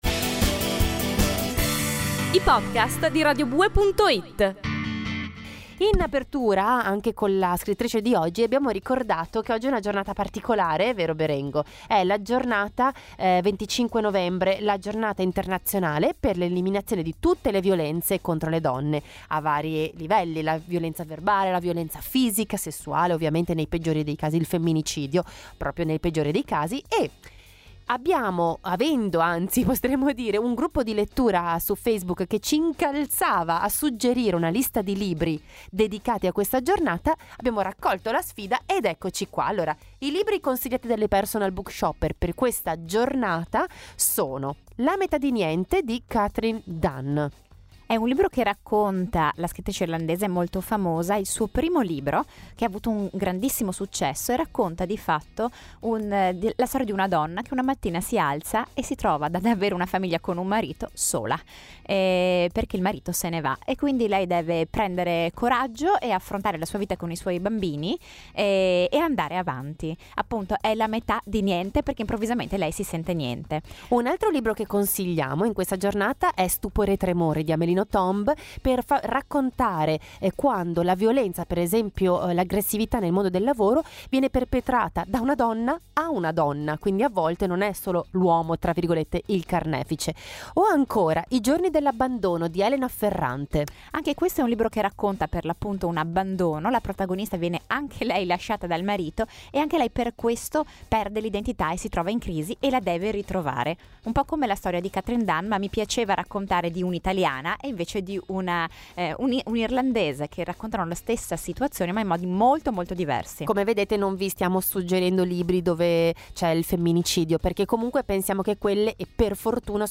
Se volessimo riflettere sulla nostra condizione, proprio in questa giornata ecco una lista di libri consigliati sul tema della violenza contro le donne. Storie di donne e di uomini, raccontate in diretta radiofonica.